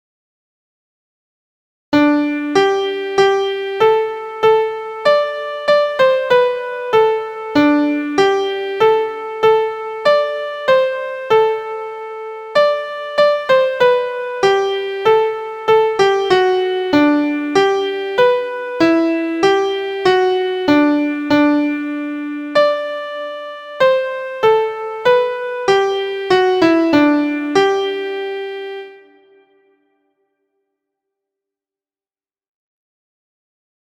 Centered around the fifth (So) of the major scale
before ending in G Major.
• Origin: USA – Traditional Folk Song
• Key: G Major
• Time: 4/4
• Form: through composed
• Musical Elements: notes: half, quarter, eighth; rest: quarter; pickup beat, tempo: giocoso/joyful/merry,
animato/animated